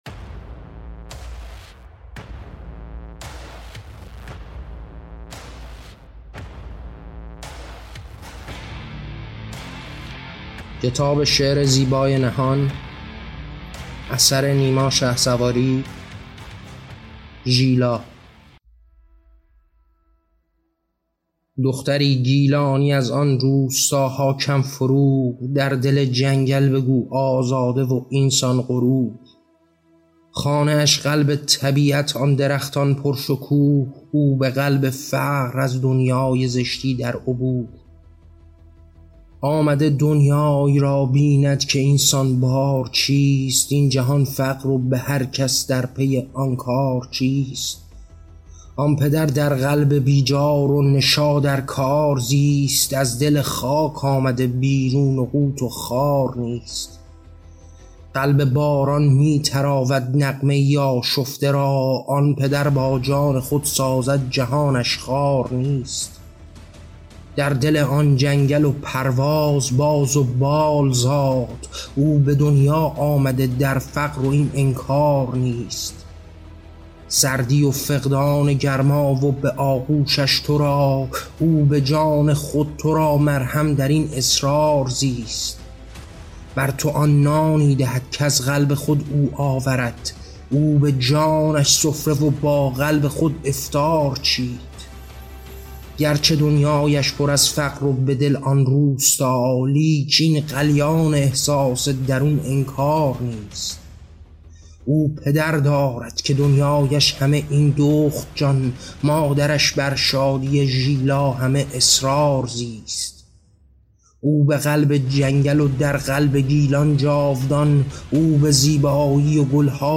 کتاب شعر صوتی زیبای نهان؛ داستان کوتاه ژیلا: مرثیه‌ای برای آزادی و خاوران